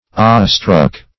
Awe-struck \Awe"-struck`\, a.